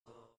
اشعار اربعین به همراه سبک/نوحه -( می رویم به ذکر یا زهرا )